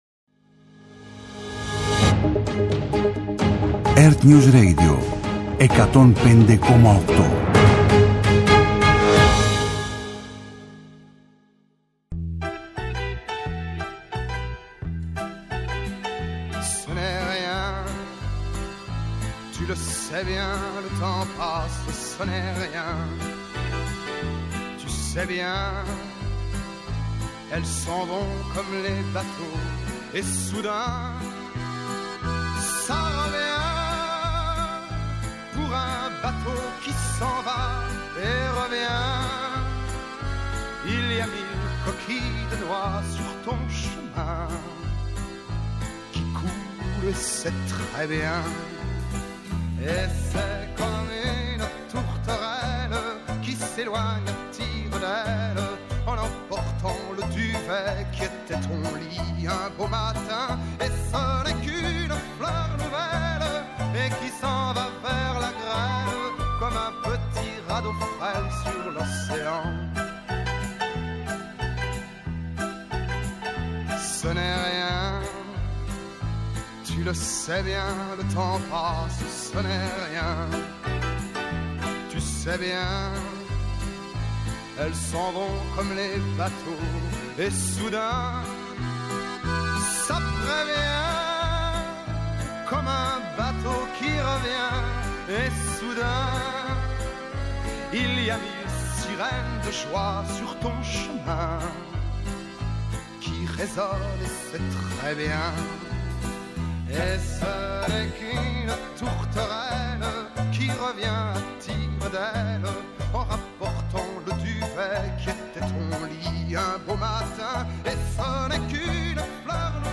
-Ο Γιώργος Παπανικολάου, Δήμαρχος Γλυφάδας
Ενημέρωση με έγκυρες πληροφορίες για όλα τα θέματα που απασχολούν τους πολίτες. Συζήτηση με τους πρωταγωνιστές των γεγονότων. Ανάλυση των εξελίξεων στην Ελλάδα και σε όλο τον πλανήτη και αποκωδικοποίηση της σημασίας τους. ΕΡΤNEWS RADIO